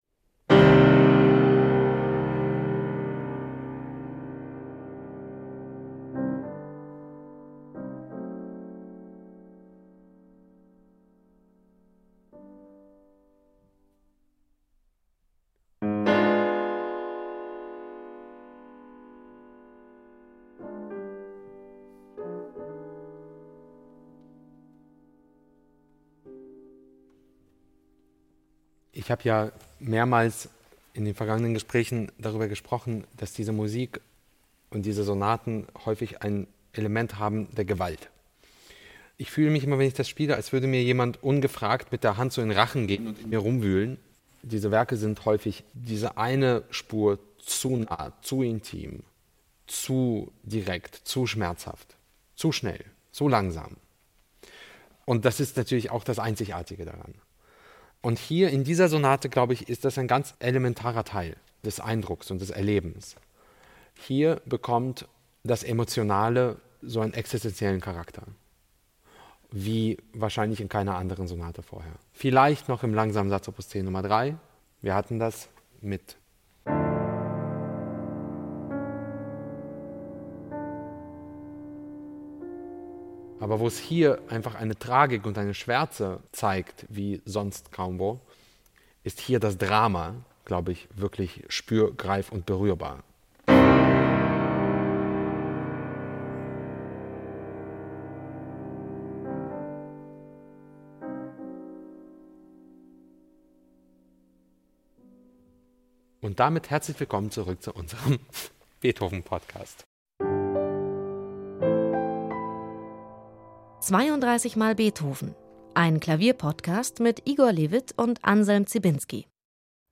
Furios illustriert von Igor Levit am Klavier.